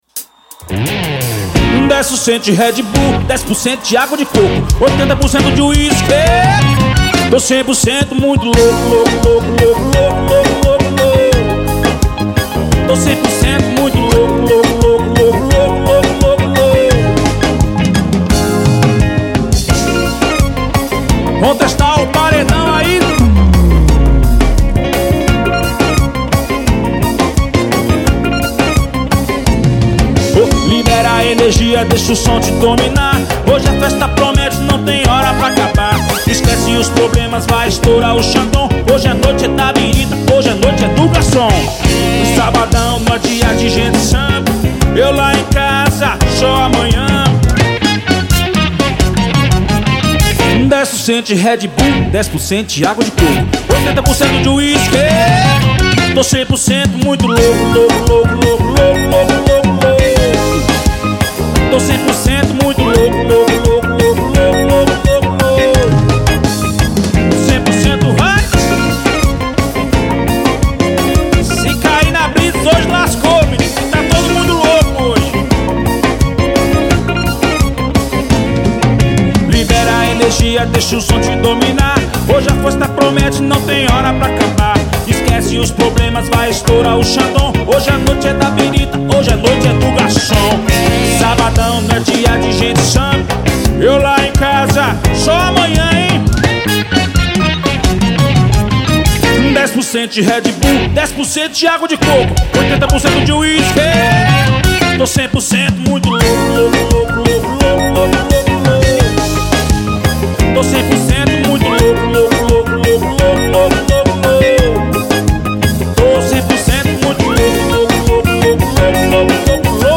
Cd promocional ao vivo venda proibida.